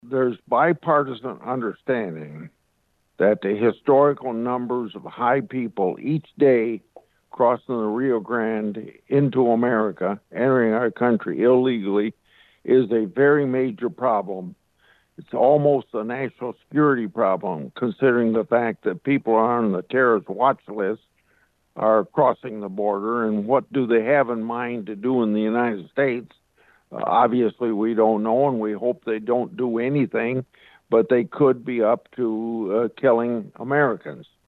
(Washington D.C.) Iowa Senator Chuck Grassley, appearing on his weekly Capitol Hill report, responded to a question about a quota at the border.